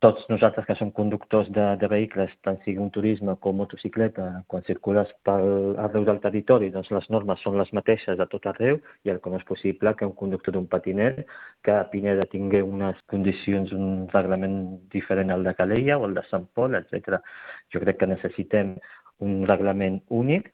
En aquest punt, el tinent d’Alcaldia de Seguretat i Mobilitat, Soufian Laroussi, reclama una normativa única i general per a tots els vehicles de mobilitat personal, per evitar canvis de criteri d’una població a una altra.